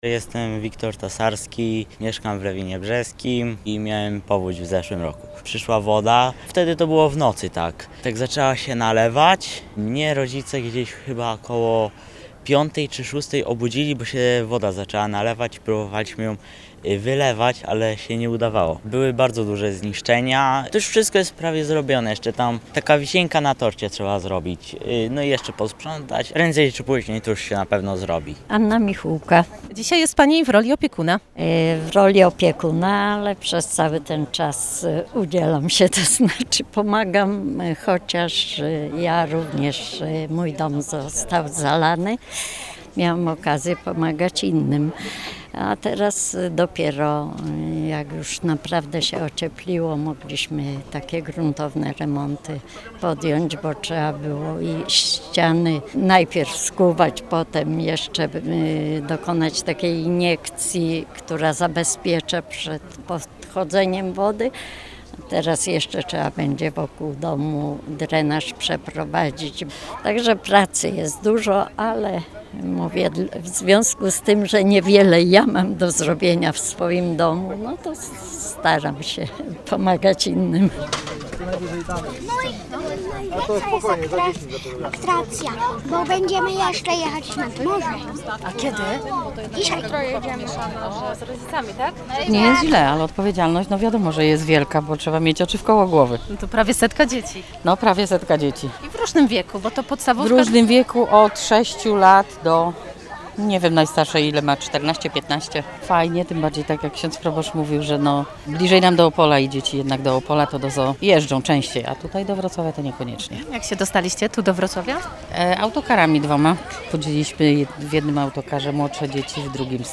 Po wylaniu Nysy Kłodzkiej we wrześniu ub. roku Lewin Brzeski był w dramatycznej sytuacji, a skutki żywiołu utrzymywały się przez wiele miesięcy. Mówią nam mieszkańcy, uczestnicy wyjazdu